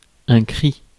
Ääntäminen
IPA: /kʁi/